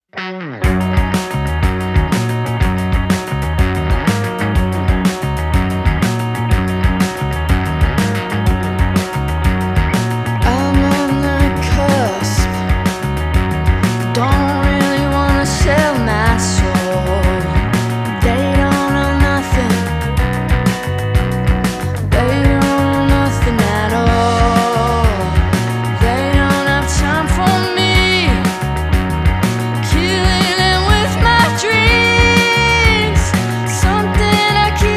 Жанр: Рок / Альтернатива
Alternative, Indie Rock